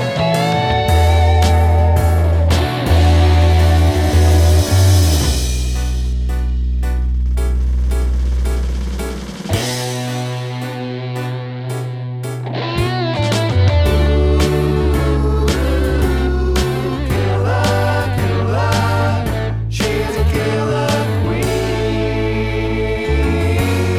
Down One Semitone Rock 3:09 Buy £1.50